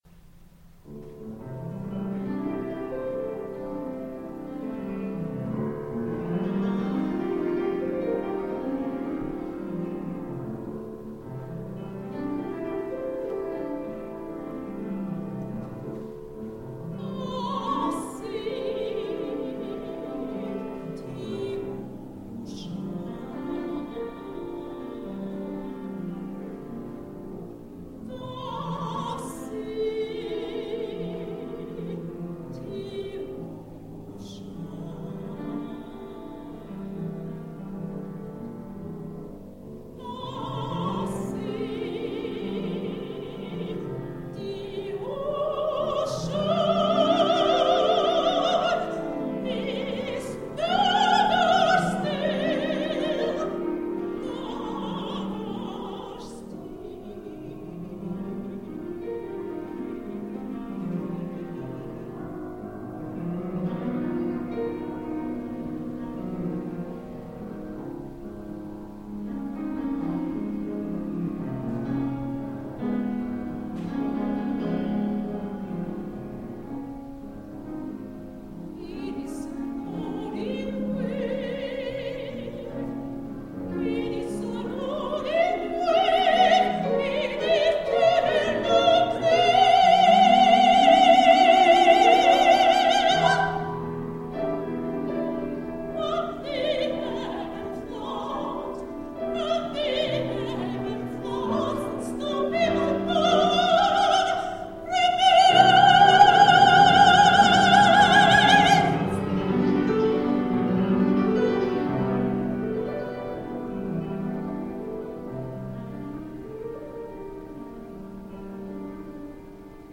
ART SONG